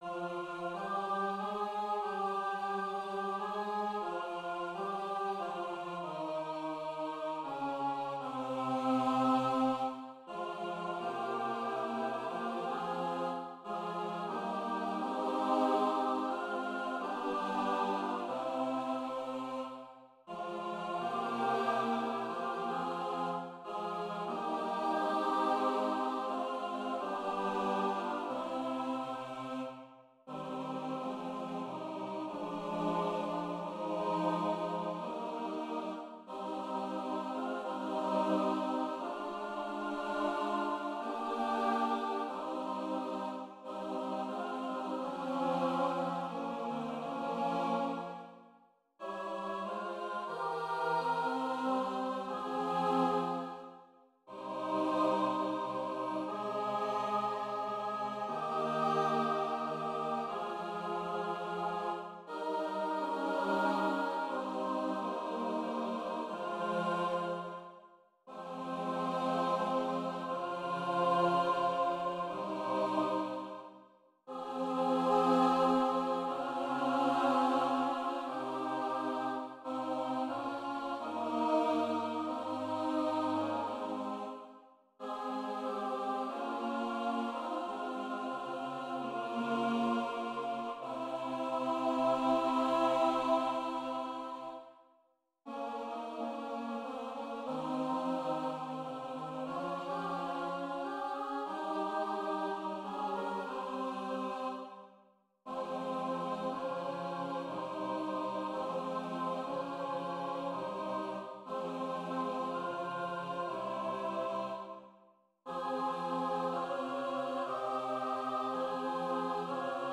Voicing/Instrumentation: SATB